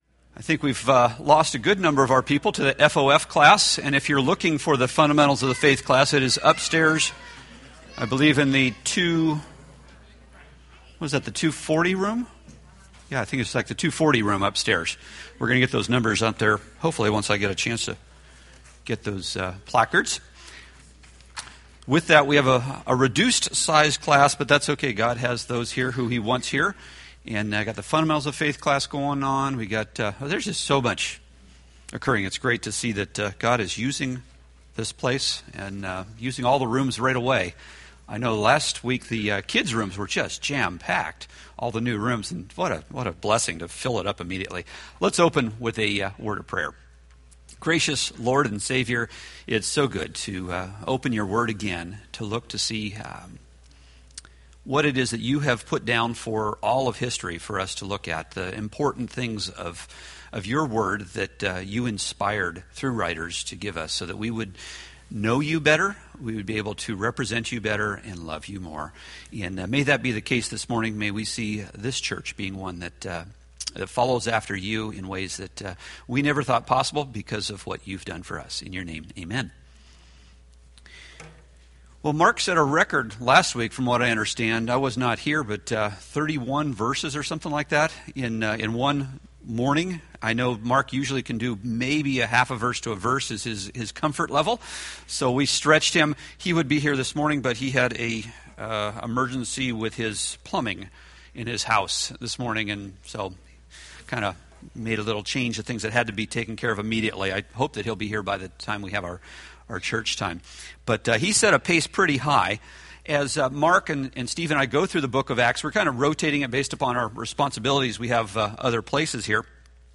Acts Class - Week 12